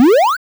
Add sound effects
Powerup7.wav